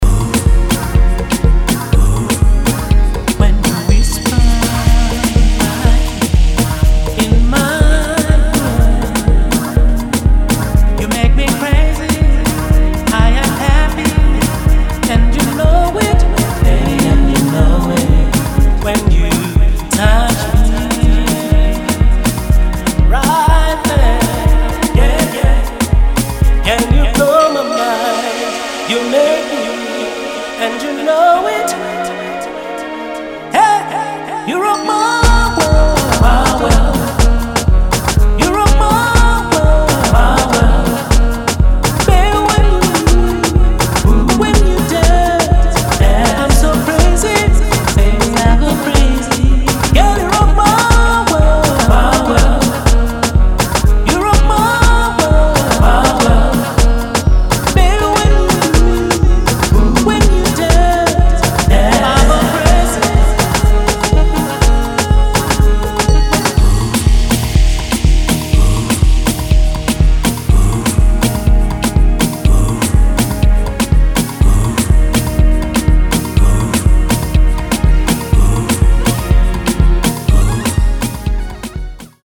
パーカッシヴなトラックに伸びやかなヴォーカルが気持ちイイ1枚！